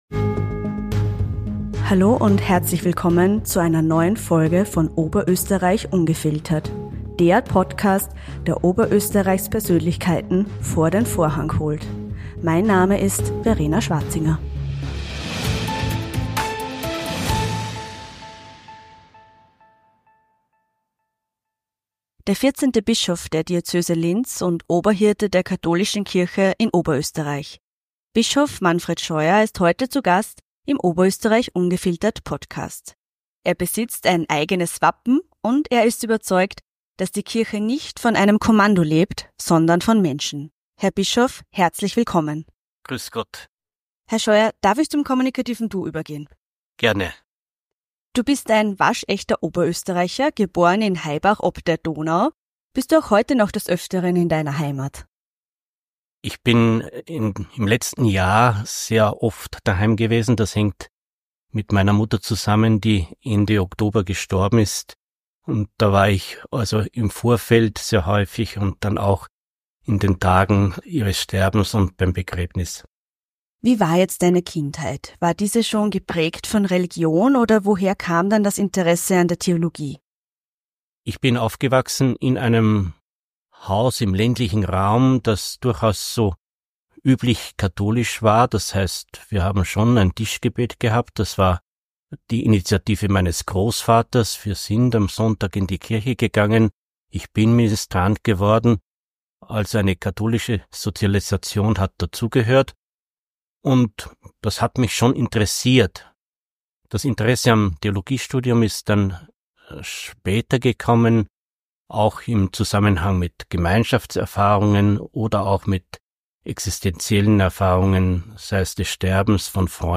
In dieser besonderen Episode von Oberösterreich ungefiltert haben wir die Ehre, den 14. Bischof der Diözese Linz, Manfred Scheuer, als Gast zu begrüßen.